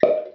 wine bottle pop
An Australian wine producer requested the "pop" of a wine bottle being opened.